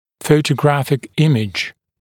[ˌfəutə’græfɪk ‘ɪmɪʤ][ˌфоутэ’грэфик ‘имидж]фотоизображение